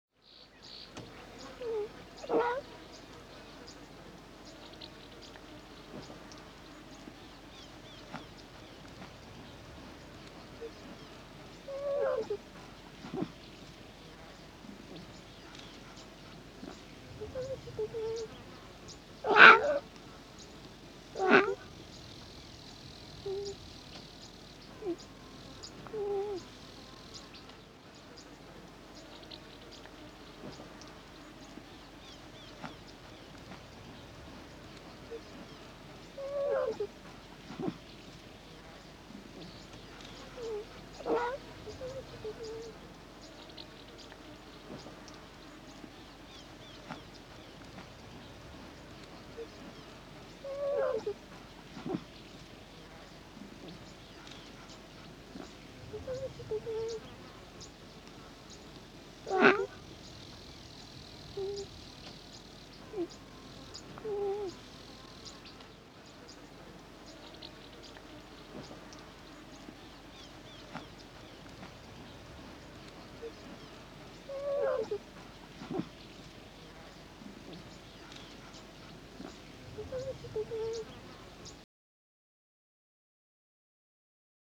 Lion Cub Mewing Sound
animal
Lion Cub Mewing